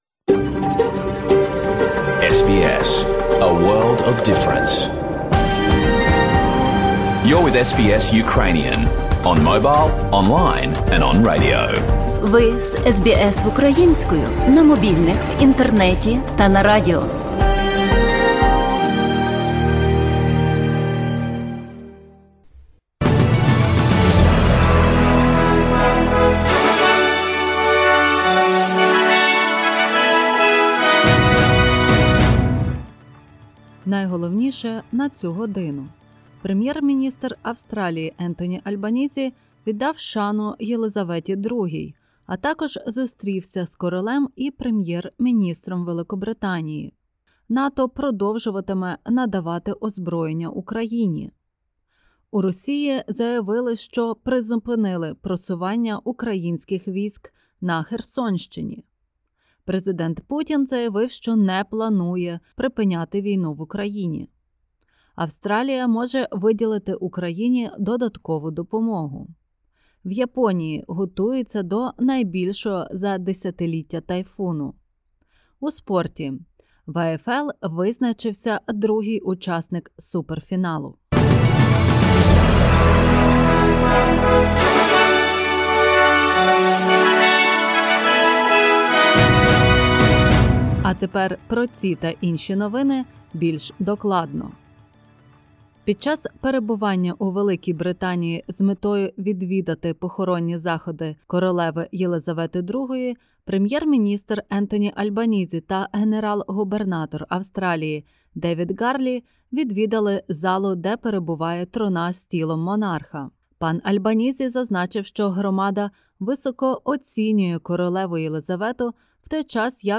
SBS News in Ukrainian – 18/09/2022